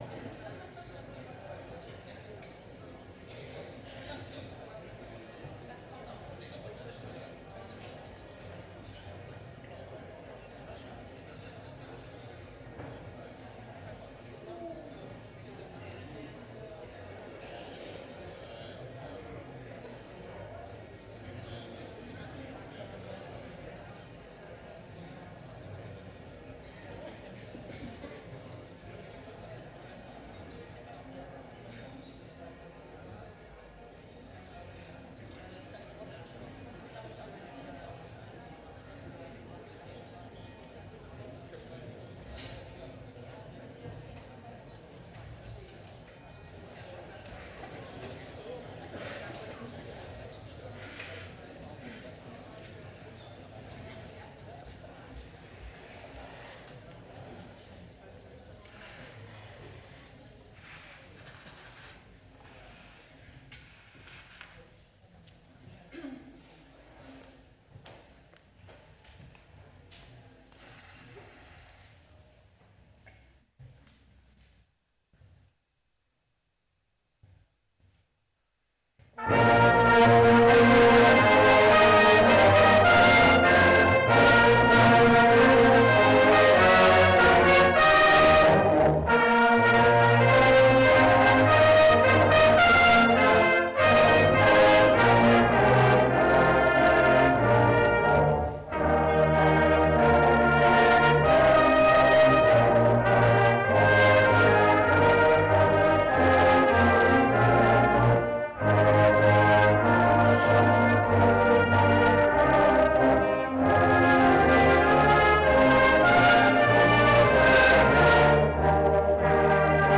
Gottesdienst - 31.08.2025 ~ Peter und Paul Gottesdienst-Podcast Podcast